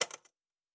pgs/Assets/Audio/Fantasy Interface Sounds/UI Tight 25.wav
UI Tight 25.wav